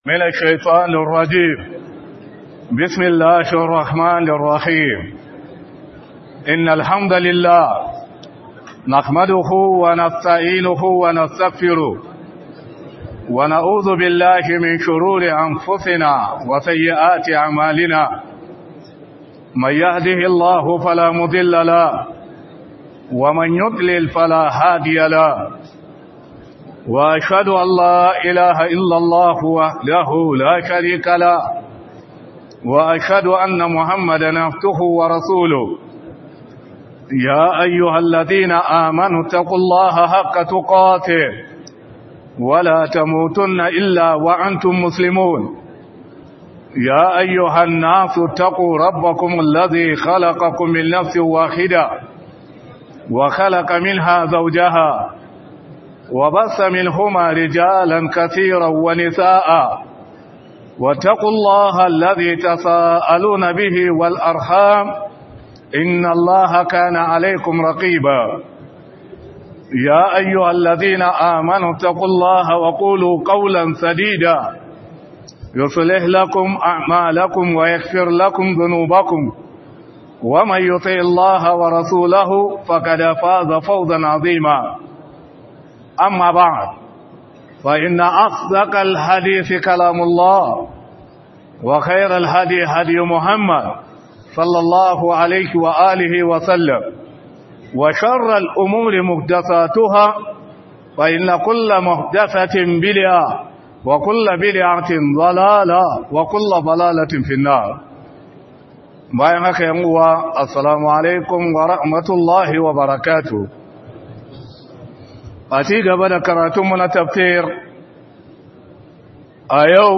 Ramadan Tafsir